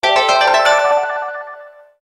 SMS Alert